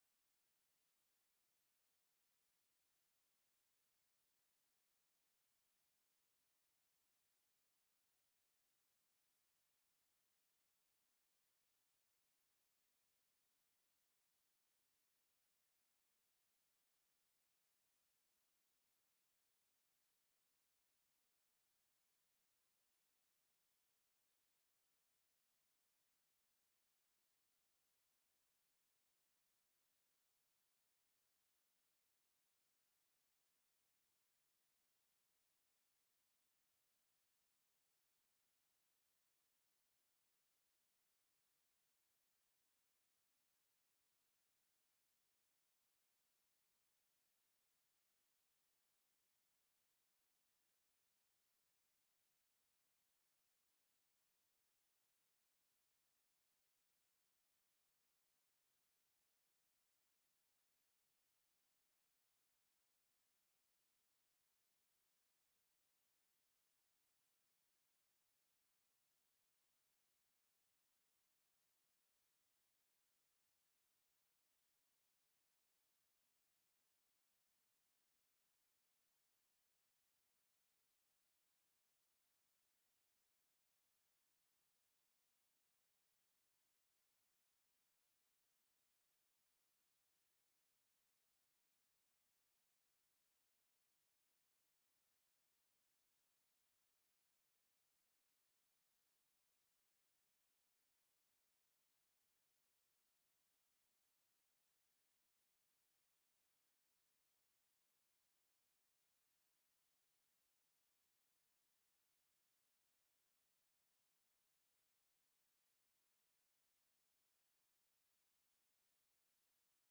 Jesus Only — Faith Sermon
Jesus-Only-Faith-Sermon-Audio-CD.mp3